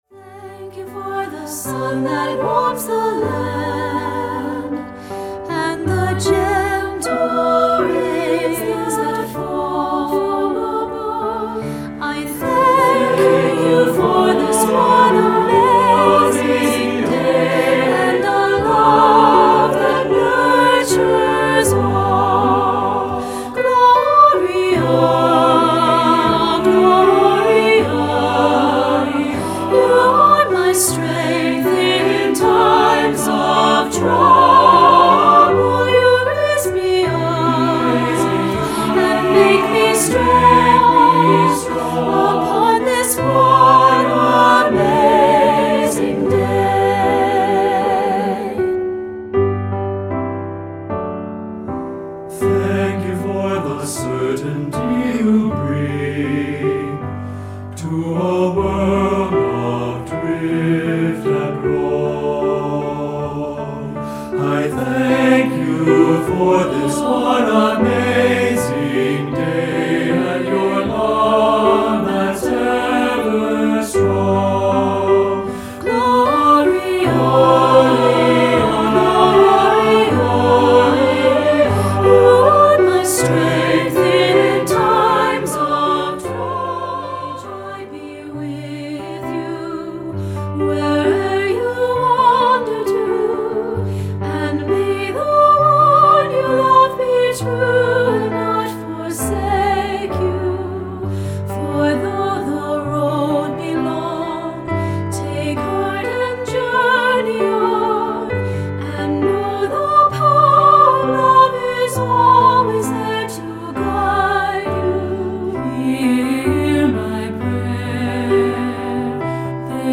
Choeur Mixte (SATB)